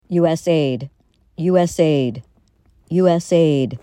USACKAS, VYGAUDAS vee-GAWD-uhs   oo-SHASH-kahs